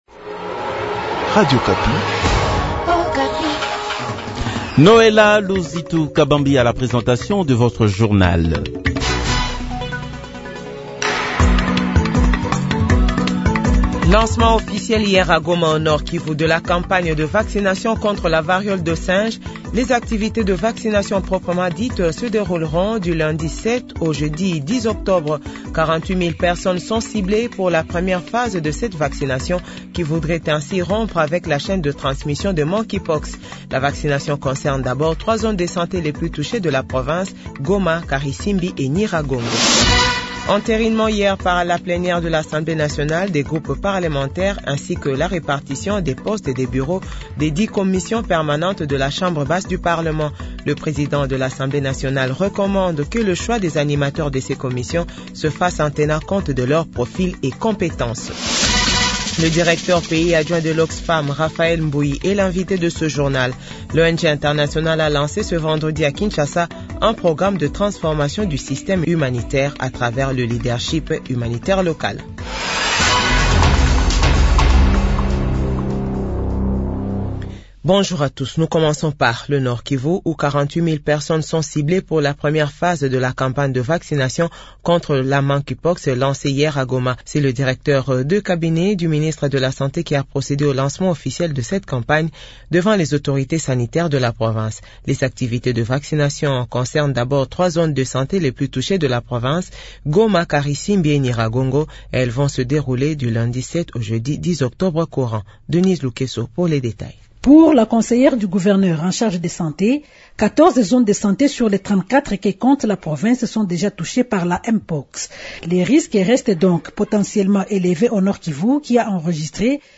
Journal 12h00